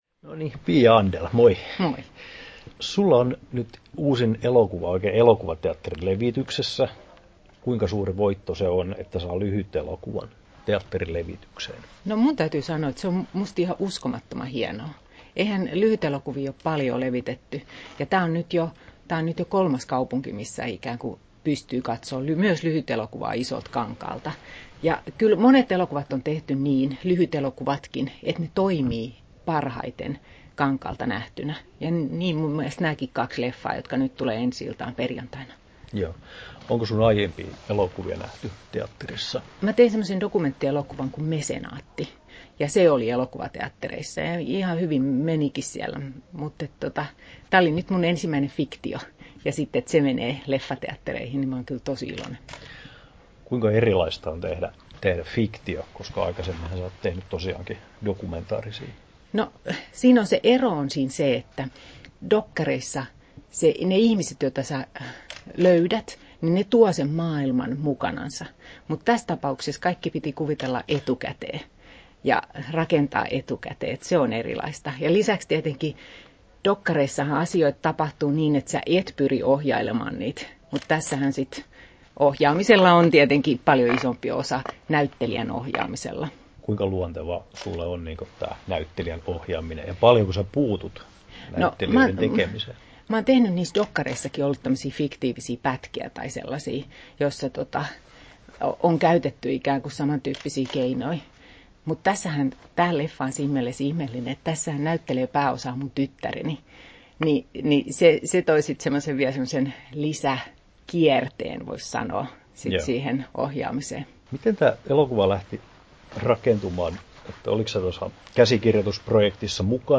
Haastattelut